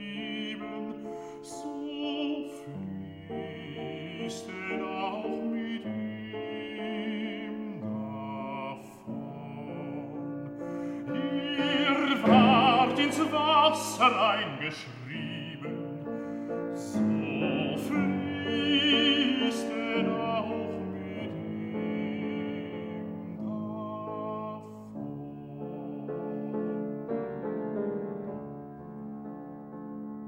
"enPreferredTerm" => "Musique vocale profane"